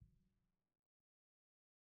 Timpani5_Hit_v1_rr1_Sum.wav